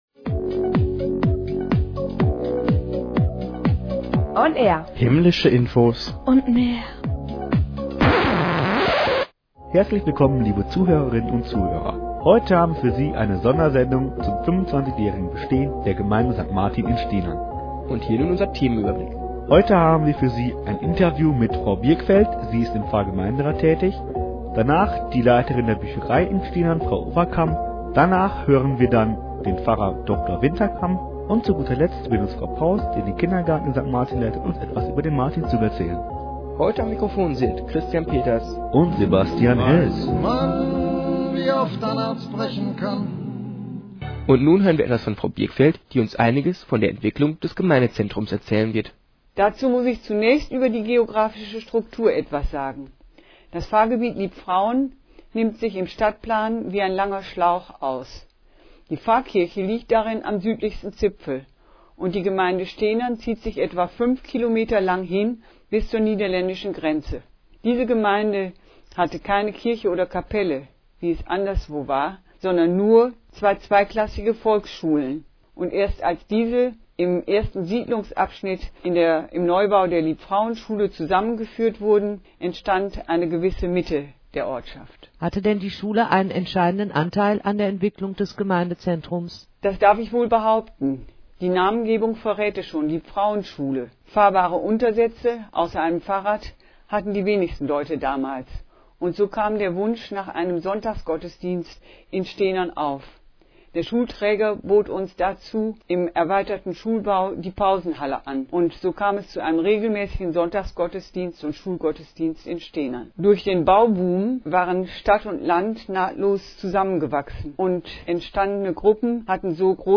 WMW-Bericht zu 25 Jahre Gemeindezentrum St. Martin